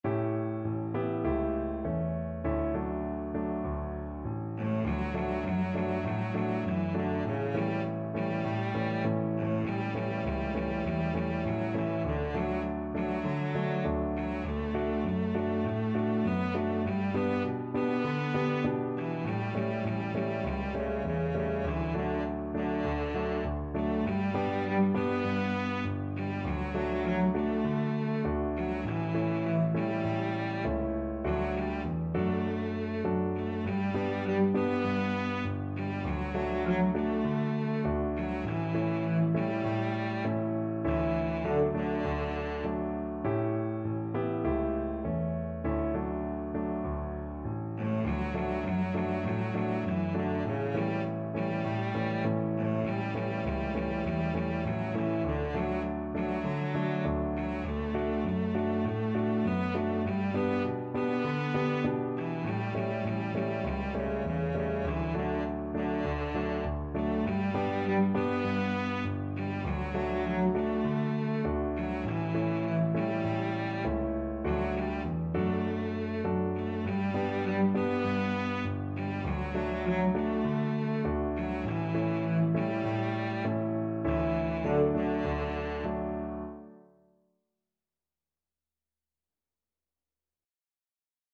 Gently =c.100